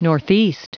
Prononciation du mot northeast en anglais (fichier audio)
Prononciation du mot : northeast